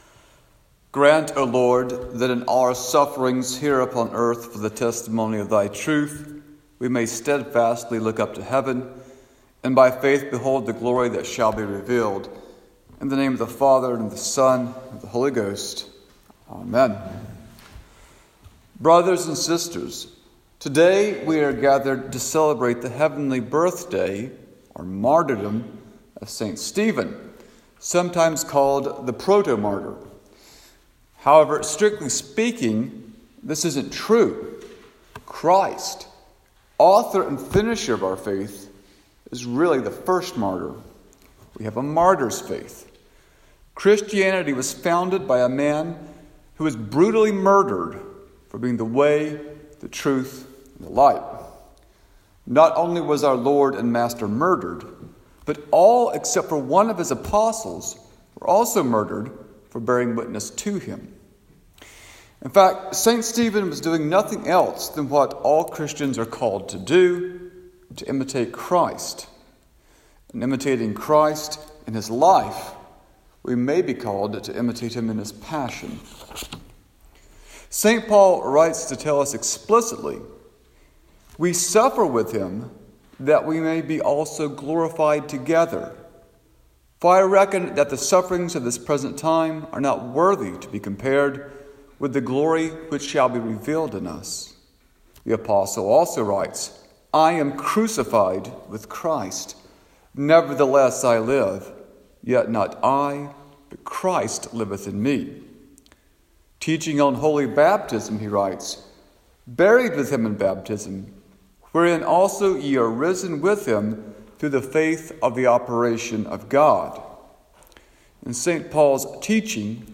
Saint George Sermons Sermon for Saint Stephen's Day